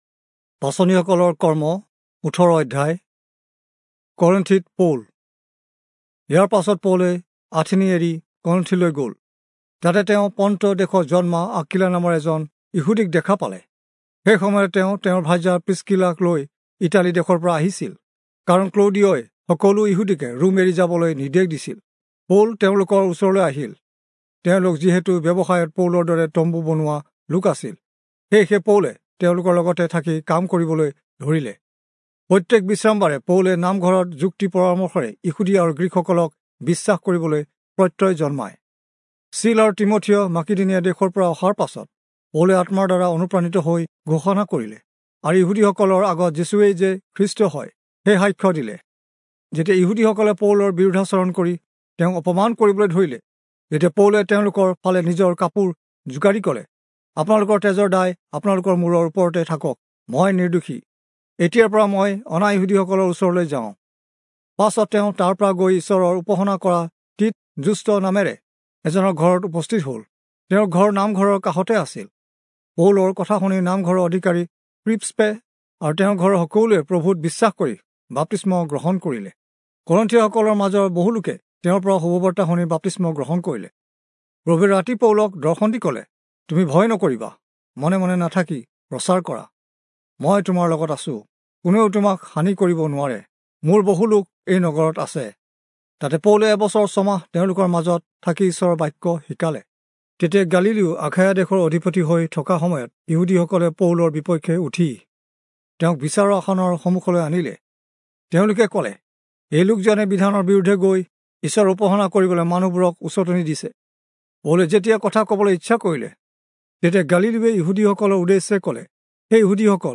Assamese Audio Bible - Acts 26 in Ncv bible version